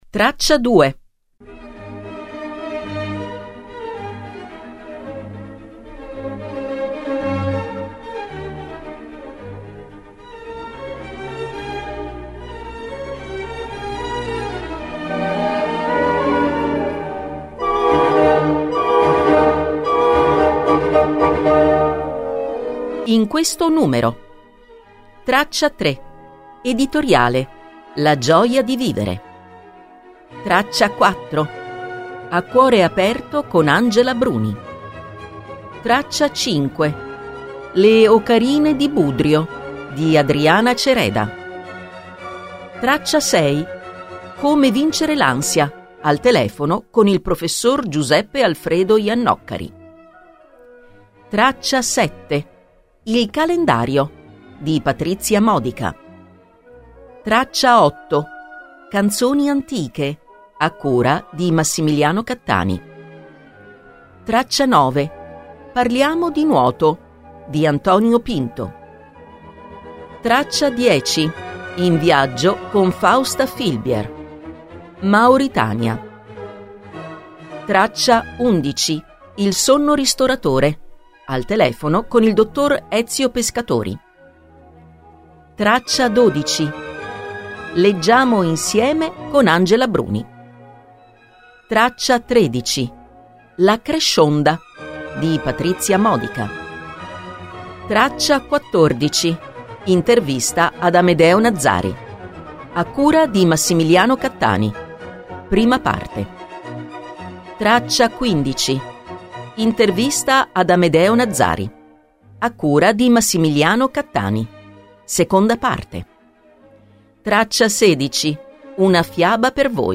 Ascoltate il sommario riportato e conoscerete questa audiorivista: